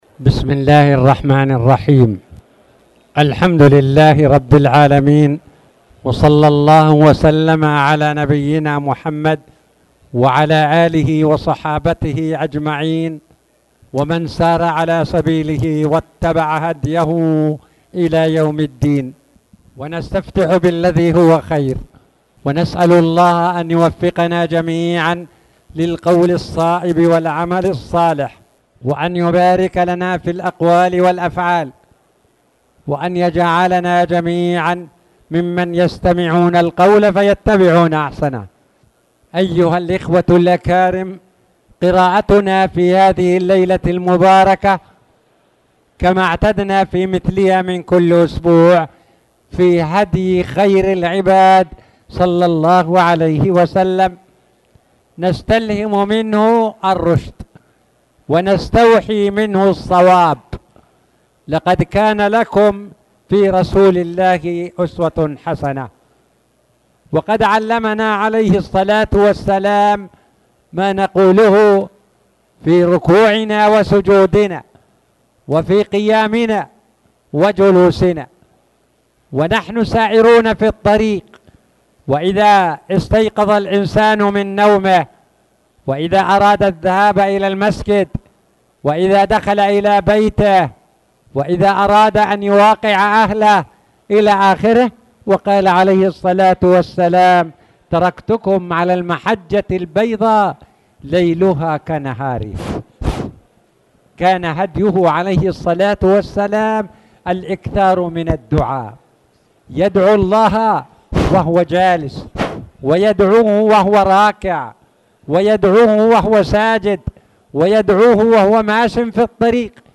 تاريخ النشر ١٠ جمادى الآخرة ١٤٣٨ هـ المكان: المسجد الحرام الشيخ